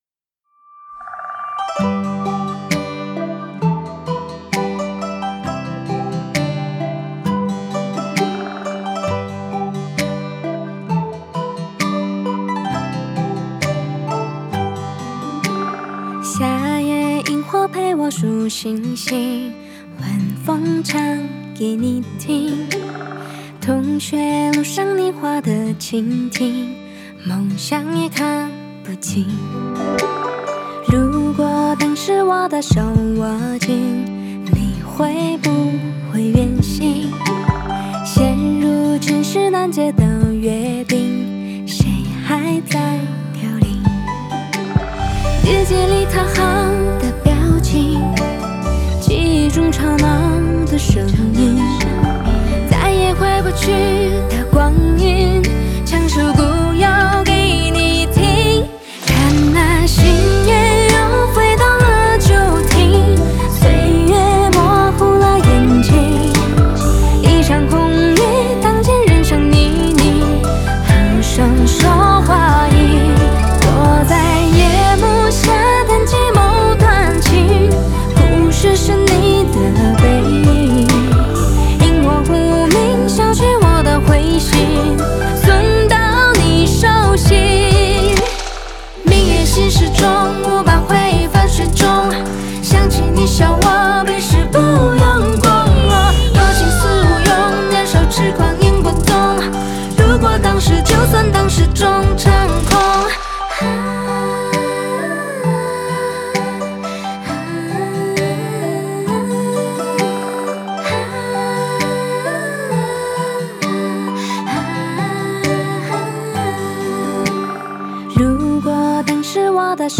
Ps：在线试听为压缩音质节选，体验无损音质请下载完整版